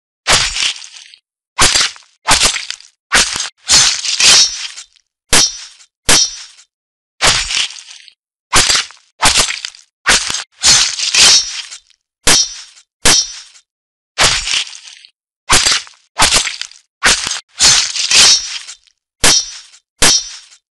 Efek Suara Pisau
Kategori: Suara senjata tempur
Keterangan: Tersedia berbagai pilihan bunyi pisau/ sound effect Knife yang tajam dan realistis, cocok digunakan di semua jenis HP dan aplikasi edit video.
efek-suara-pisau-id-www_tiengdong_com.mp3